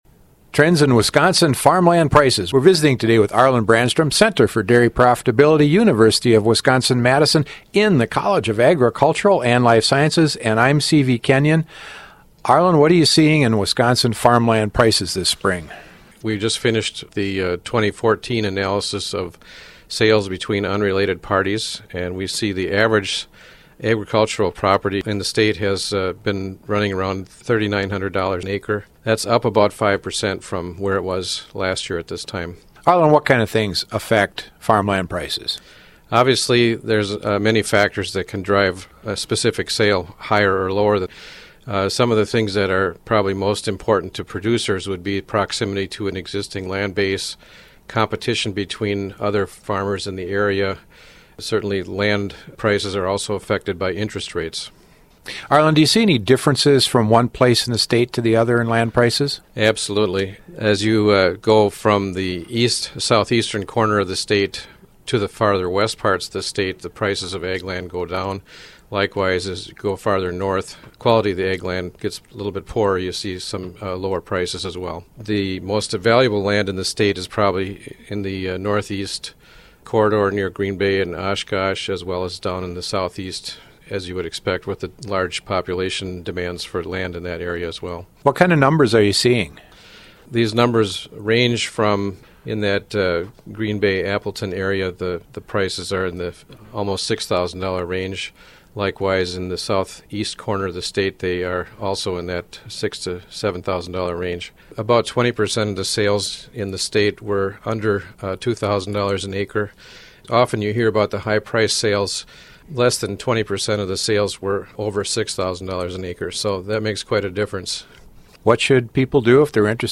2:47 – Lead out